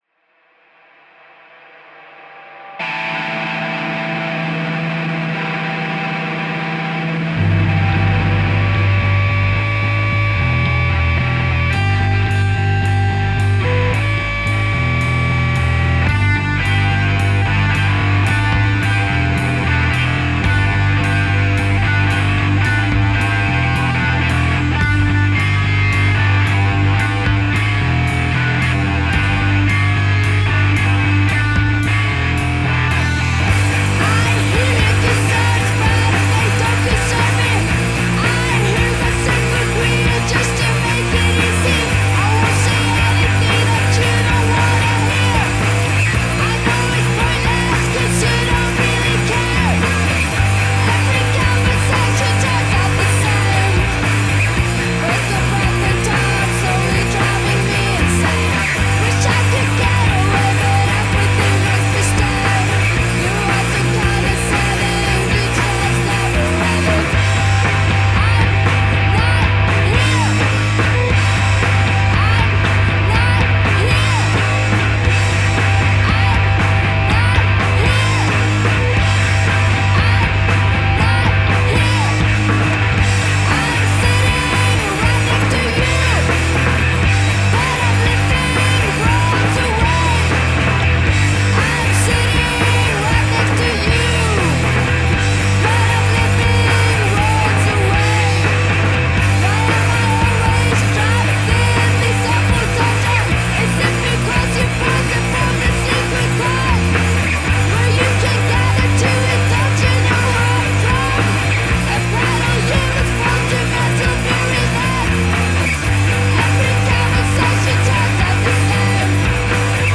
a dark trudge brimming with resentment.